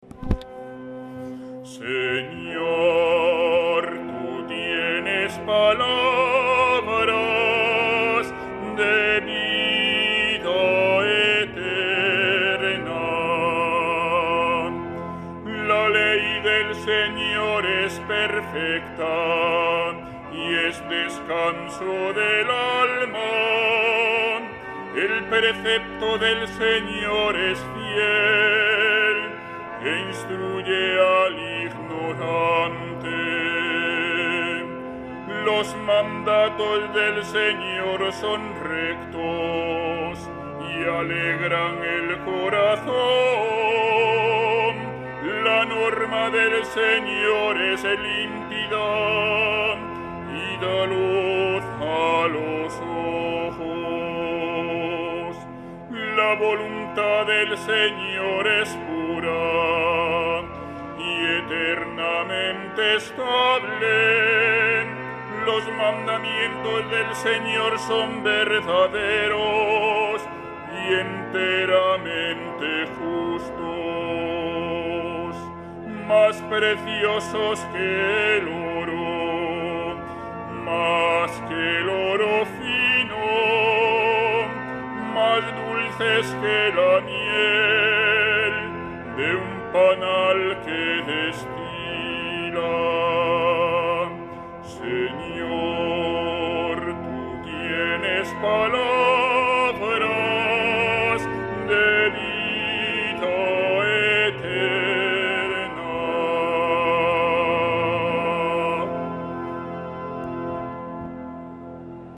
Salmo Responsorial [1.753 KB]   Versículo antes del Evangelio [594 KB]